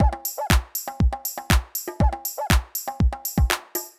ITA Beat - Mix 2.wav